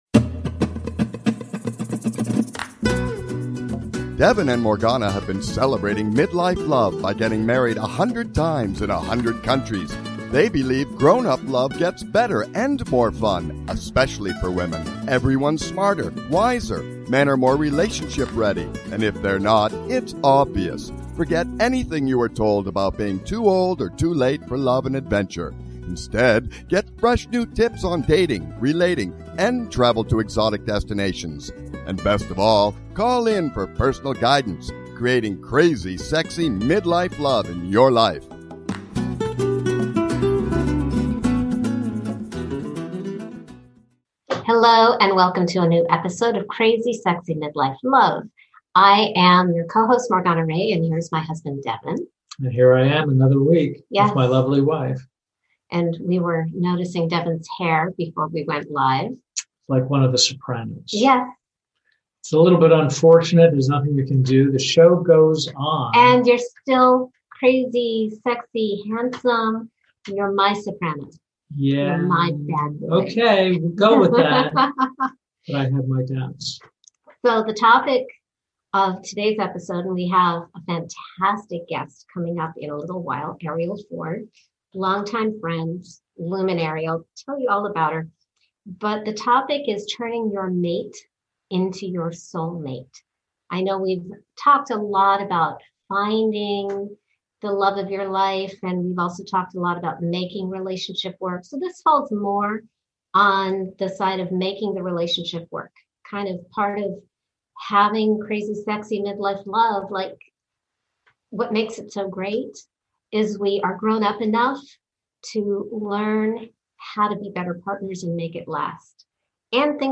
Talk Show Episode, Audio Podcast, Crazy Sexy Midlife Love